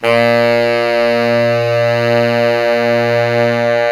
SAX_sfa#2fx  225.wav